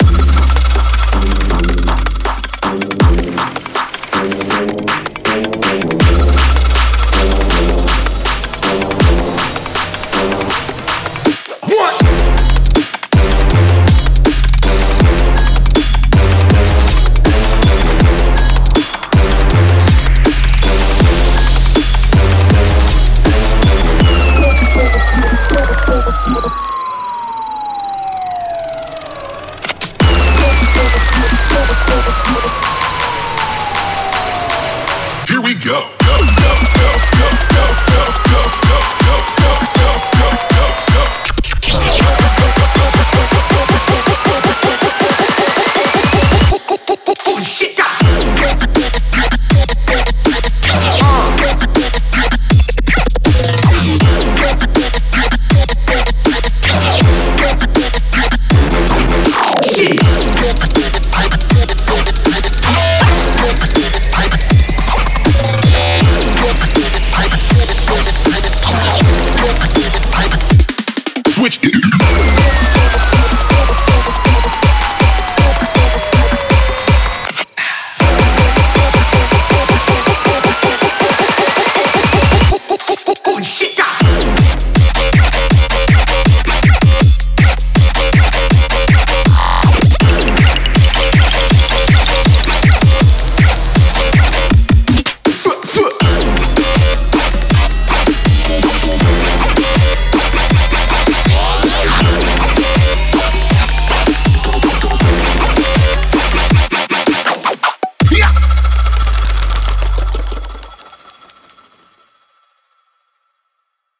3. Dubstep